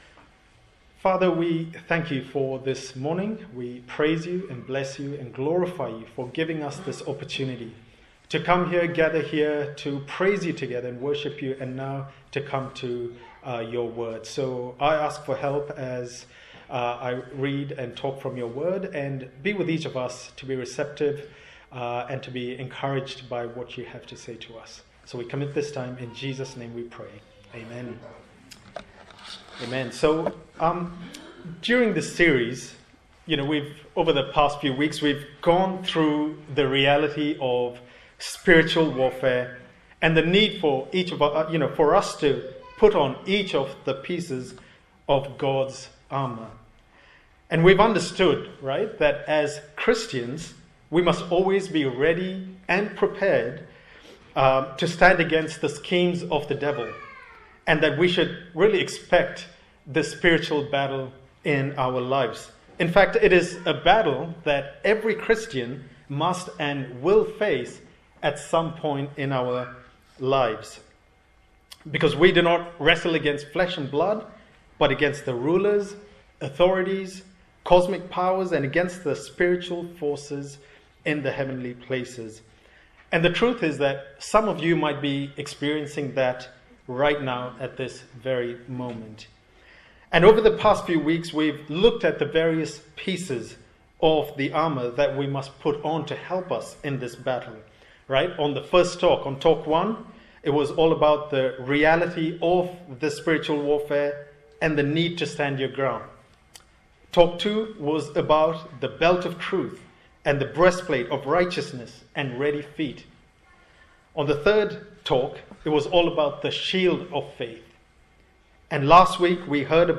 Service Type: Morning Service A sermon in the series on the Full Armour of God from Ephesians